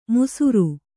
♪ musuṛu